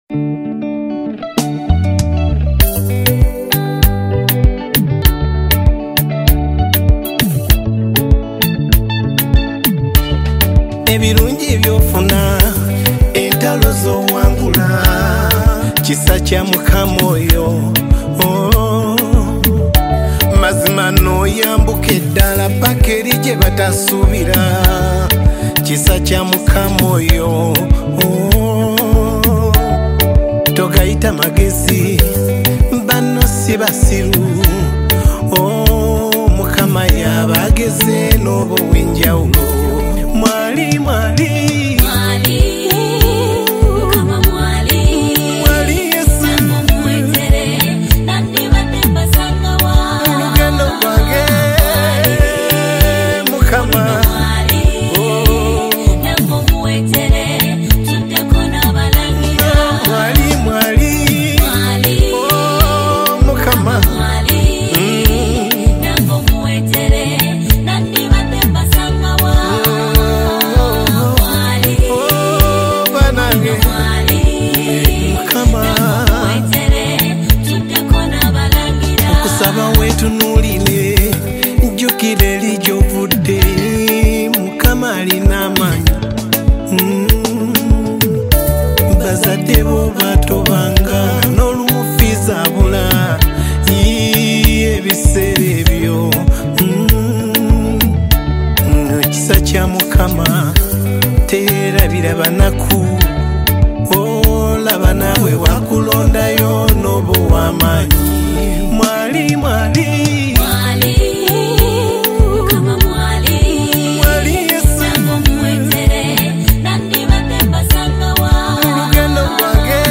Genre: RNB